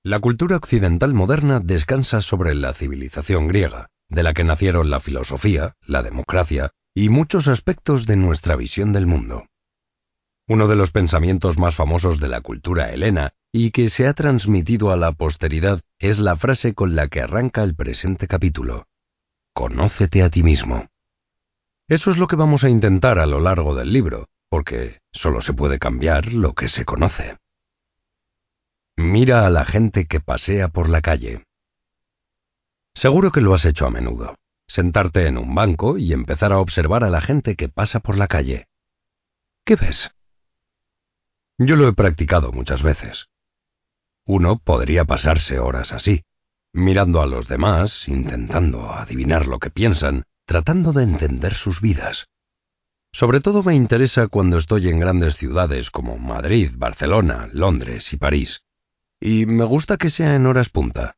audiolibro Parar para vivir mejor Guia definitiva para liberarte de la ansiedad y del ruido mental Javier Garcia Campayo